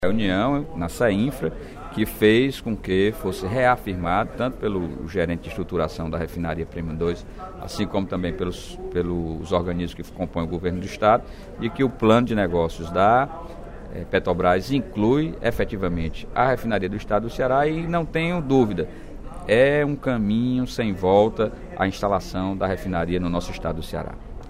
No primeiro expediente da sessão plenária desta quarta-feira (16/04), o deputado Sérgio Aguiar (Pros) comentou sobre a reunião que debateu o andamento do cronograma de ações para a instalação da Refinaria Premium II, no Complexo Industrial e Portuário do Pecém (CIPP).